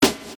You might find the higher frequencies of your drum sound ‘wooshing’ back even after you’ve faded it out.